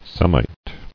[Sem·ite]